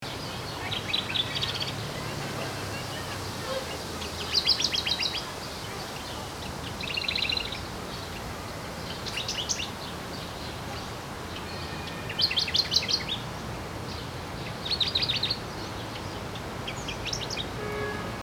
Толстоклювая пеночка
В академгородке.